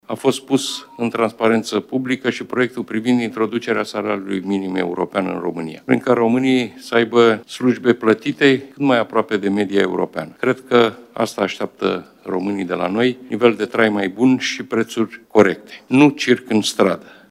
„A fost pus în transparență publică și proiectul privind introducerea salariului minim european în România, prin care românii să aibă slujbe plătite cât mai aproape de media europeană. Crede că asta așteaptă românii de la noi – nivel de trai mai bun și prețuri corecte, nu circ în stradă”, a spus premierul joi, 13 iunie, la începutul ședinței de Guvern.